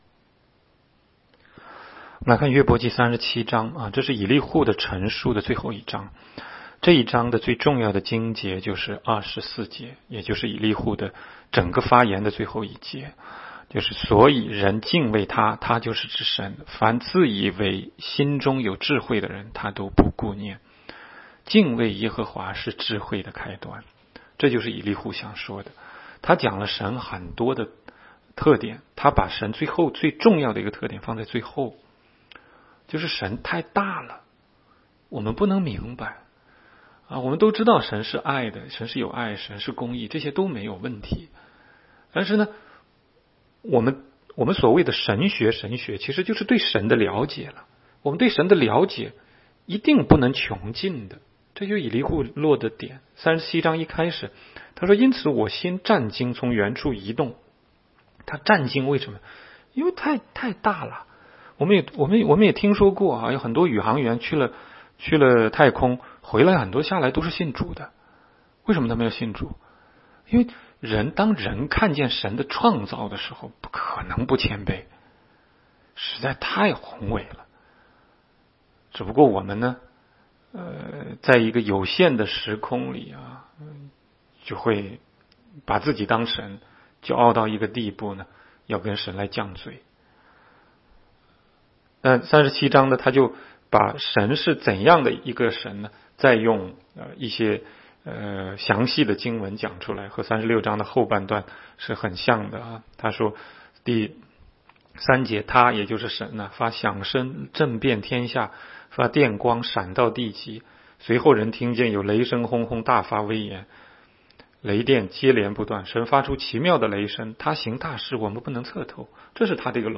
16街讲道录音 - 每日读经-《约伯记》37章